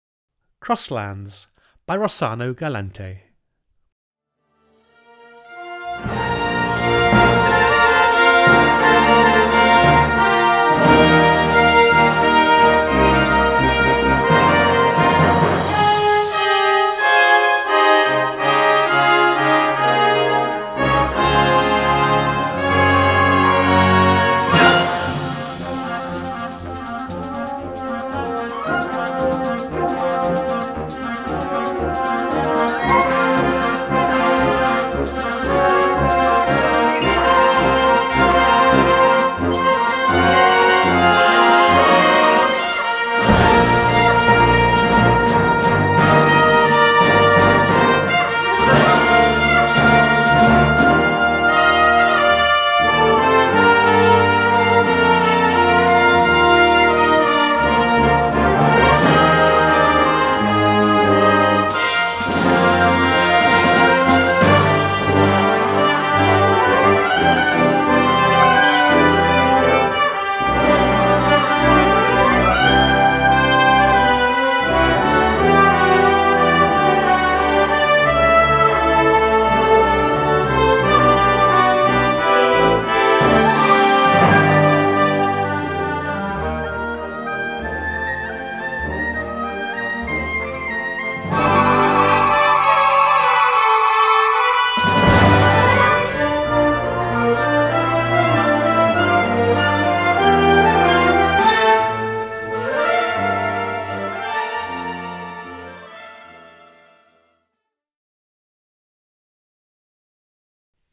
descriptive work for winds